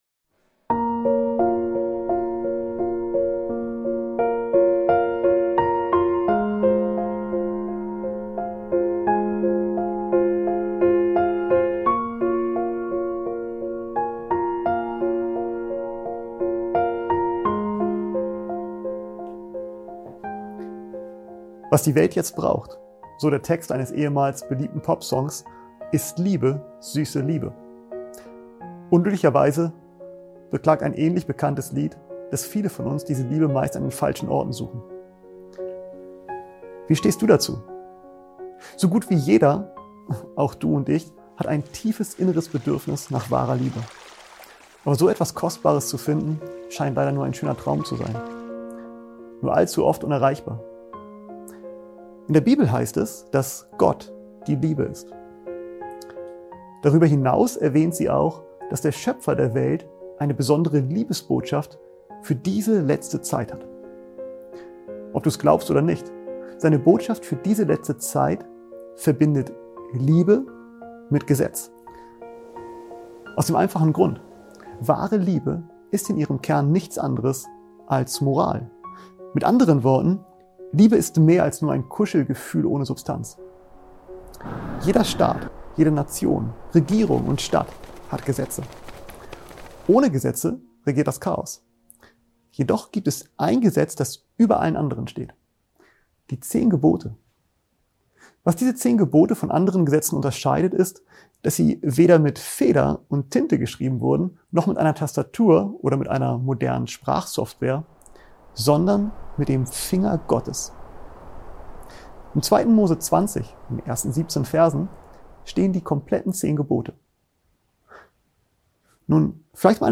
In diesem tiefgründigen Vortrag wird die Suche nach wahrer Liebe thematisiert und mit biblischen Prinzipien verknüpft. Der Referent erklärt, wie Gottes Botschaft und die Zehn Gebote In dieser sündigen Welt Frieden und Glück bringen könnten. Er ermutigt zur Rückkehr zu Jesus, dem ultimativen Geschenk der Liebe, und bietet Hoffnung auf ewiges Leben.